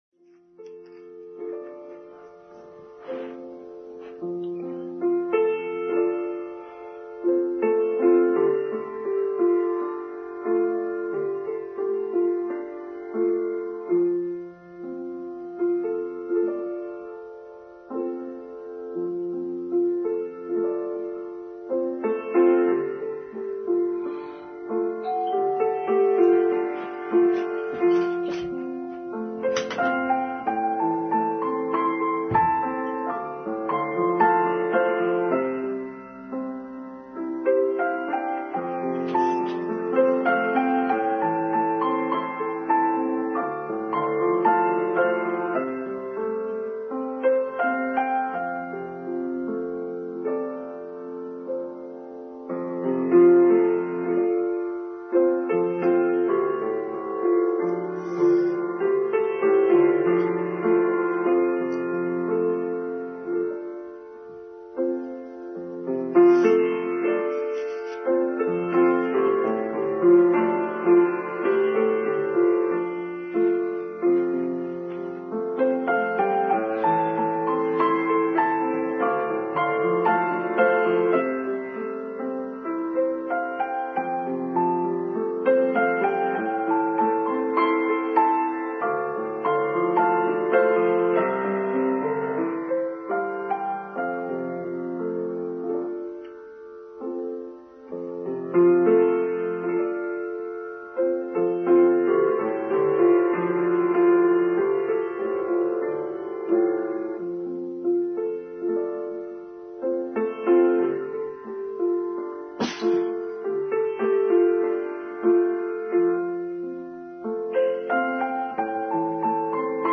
Easter Sunday: Online Service for Sunday 4th April 2021